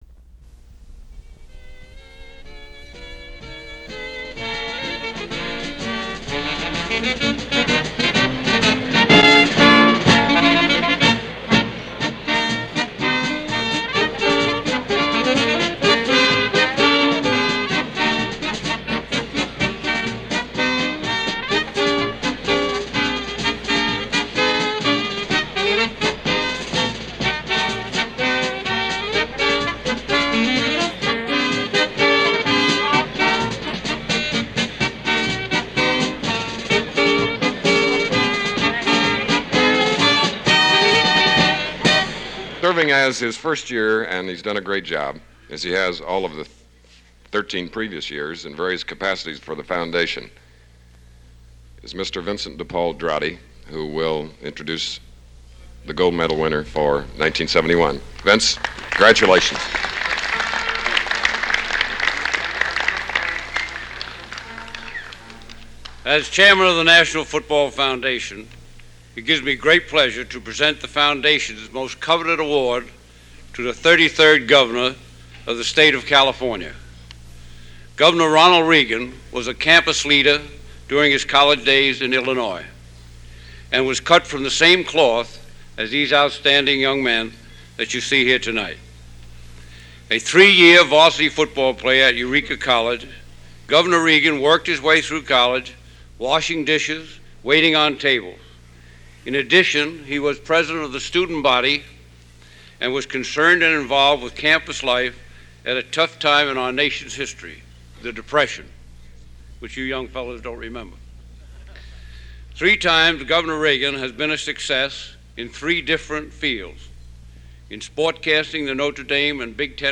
Governor Reagan Speech to National Football Foundation and Hall of Fame Dinner
Audio Reel to Reel, (original of cassette) CD copy).
MP3 Audio file Tape Number R2R-2 Date 12/07/1971 Location Waldorf Astoria hotel, New York City Tape Length 26:20